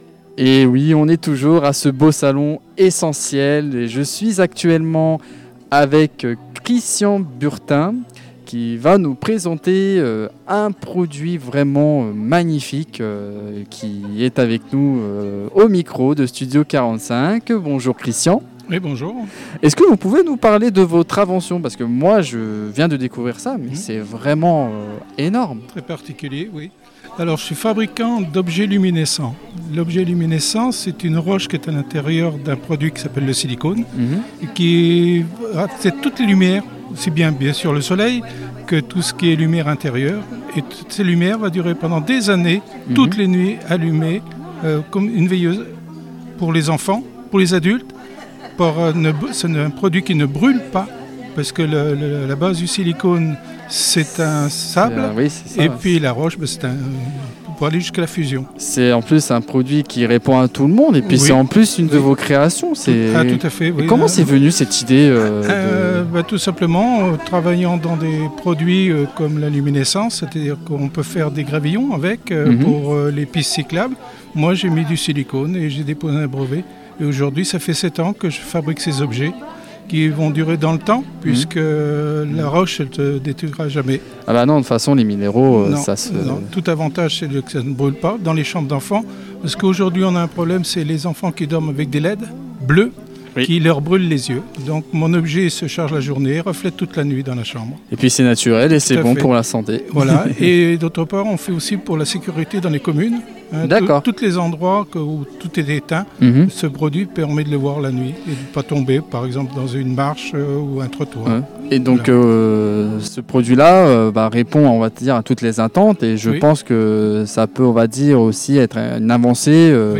Les interviews du Salon Essenti’Elles – Édition 2026
À l’occasion du Salon Essenti’Elles, organisé les 7 et 8 mars 2026 au gymnase de Châtillon-Coligny, l’équipe de Studio 45 est allée à la rencontre des organisatrices et des nombreux exposants présents durant ce week-end consacré au bien-être et à l’univers féminin.